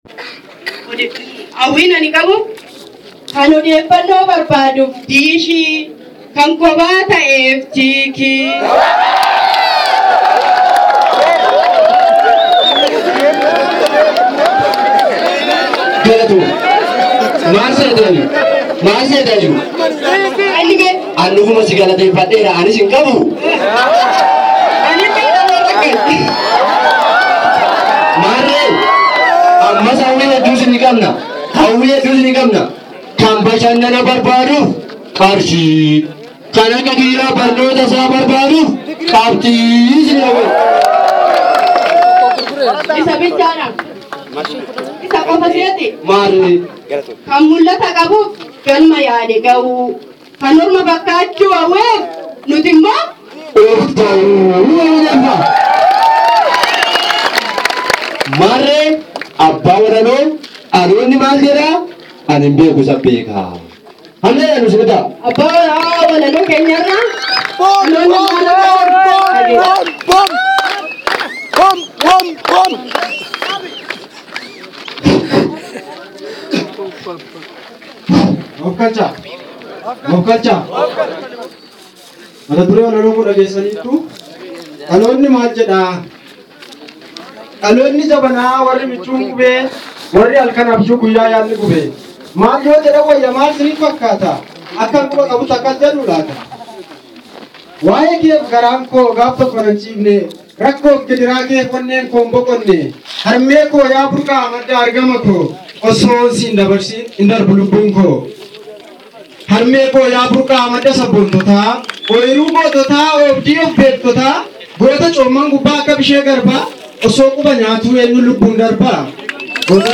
Guutummaa walaloo fi walleelee warraqaasaa kallattiin waltajjii irraa dargaggootaa fi barattoota Oromoo fi barsiisotni Oromoo fi hojjettootni ilmaan Oromoo Yuunibarsiitii Jimmaa  kumootaan lakka’aman kan qooda irraa fudhatan.